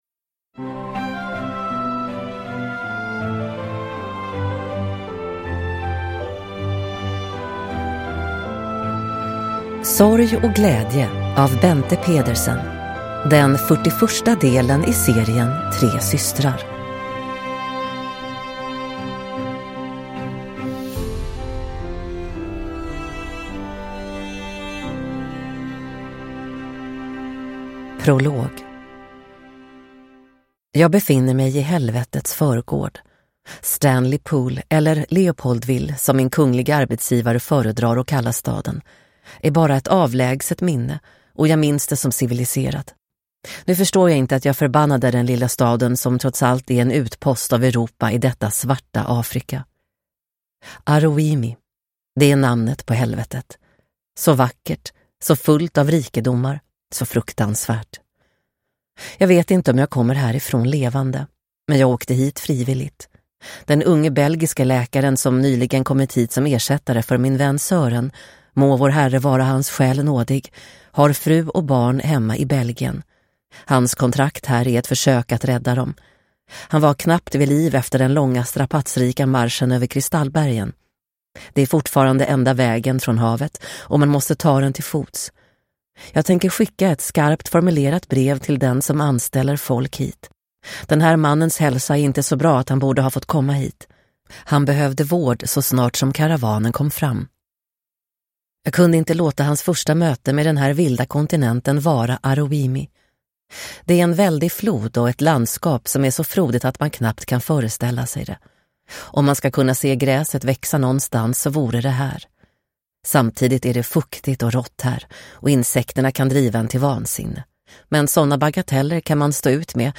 Sorg och glädje – Ljudbok – Laddas ner